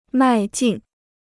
迈进 (mài jìn) Free Chinese Dictionary